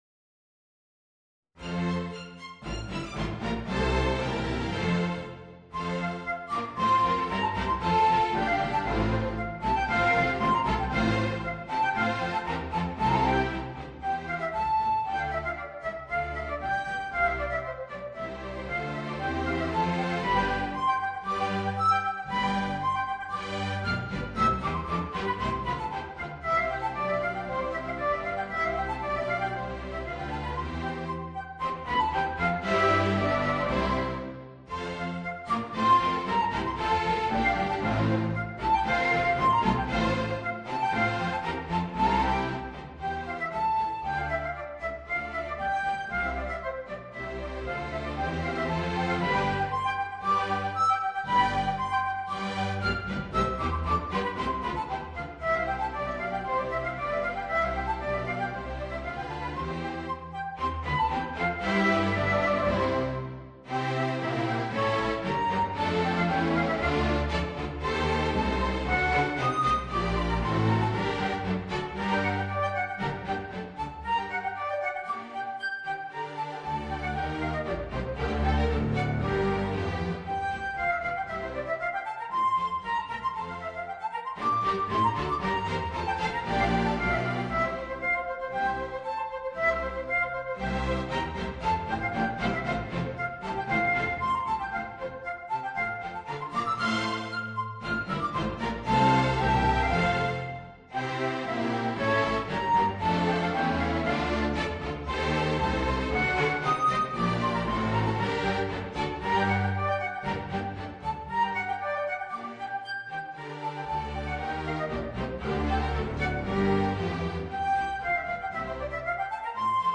Voicing: Alto Saxophone and String Orchestra